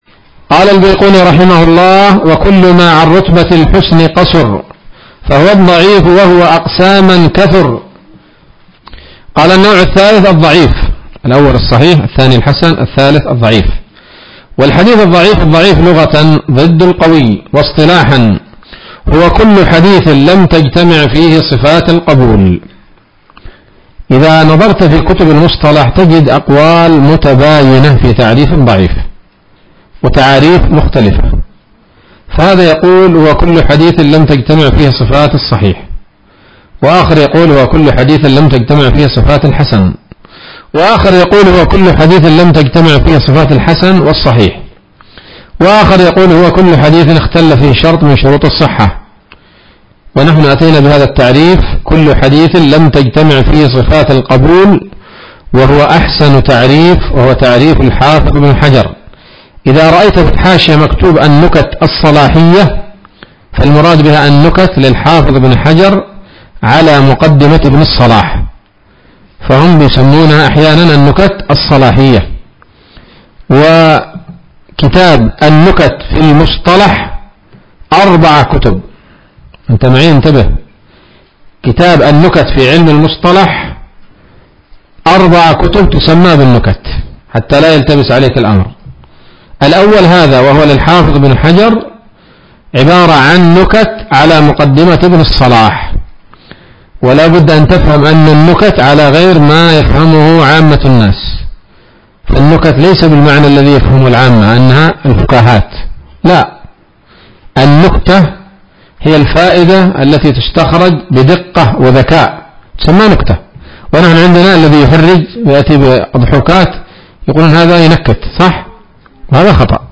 الدرس الثامن من الفتوحات القيومية في شرح البيقونية [1444هـ]